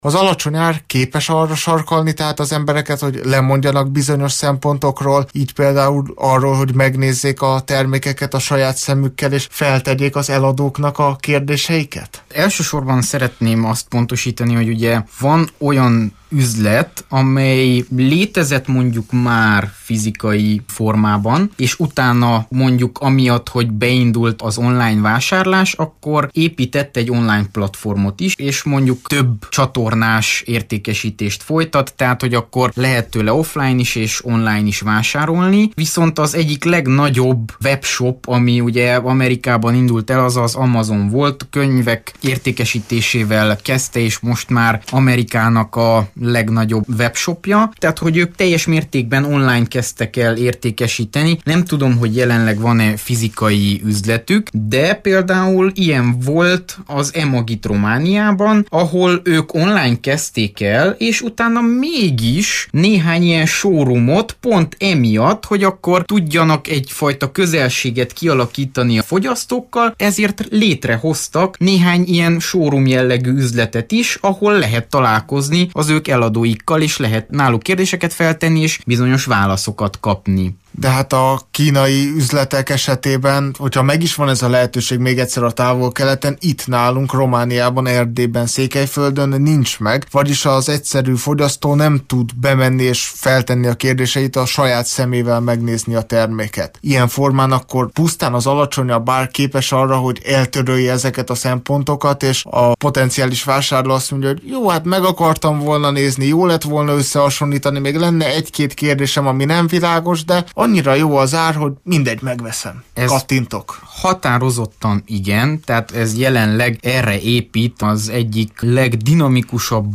beszélgetett tegnap esti Digitális Világ című műsorunkban. Ebből hallhatnak most egy részletet.